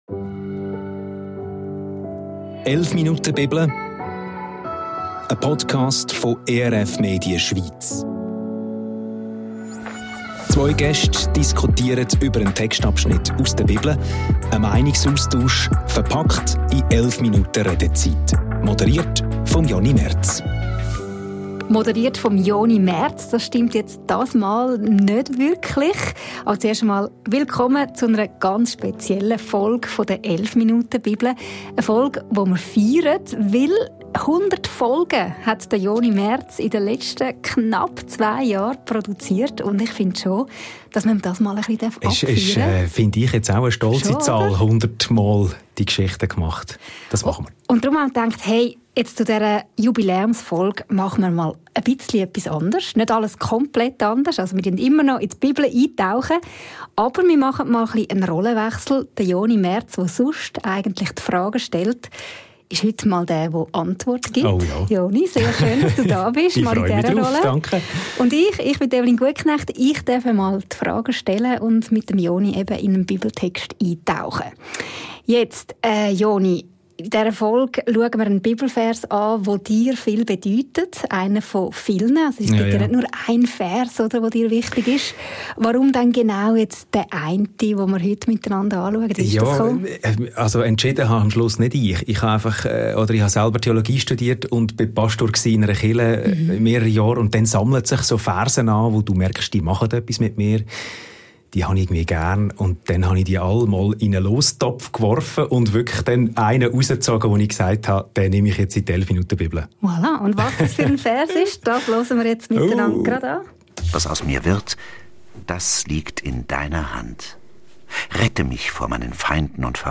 Wie immer gibt ein Text aus der Bibel den Steilpass für das Gespräch.